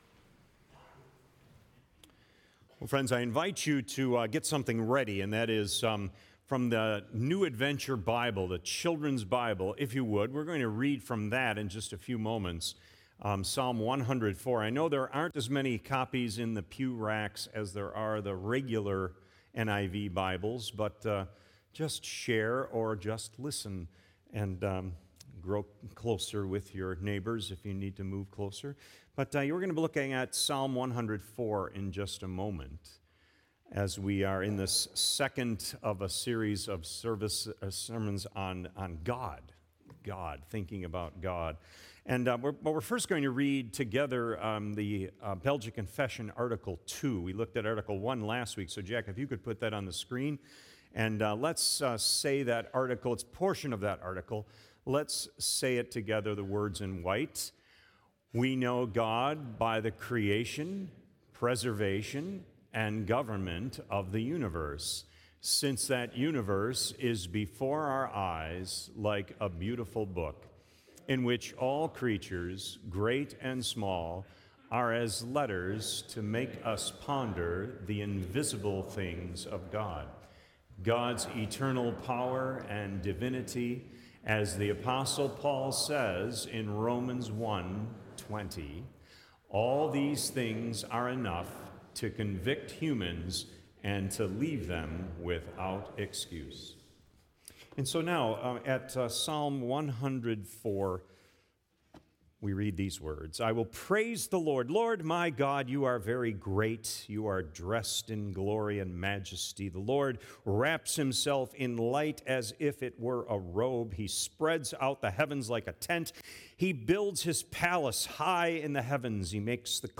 Sermon Recordings | Faith Community Christian Reformed Church
“God…Generally Speaking” January 19 2025, A.M. Service